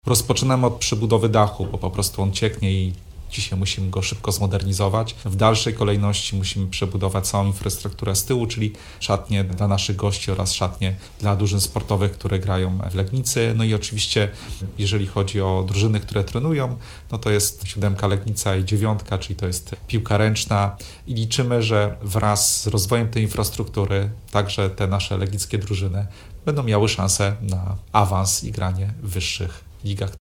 Hala widowiskowa zbudowana na przełomie lat 80-90-tych ubiegłego stulecia przy ul. Lotniczej w Legnicy wymaga gruntownej modernizacji. O tym, co zostanie zrealizowane dzięki dofinansowaniu, mówi Maciej Kupaj – Prezydent Legnicy.